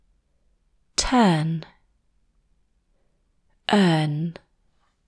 7. Adjusting question intonation for English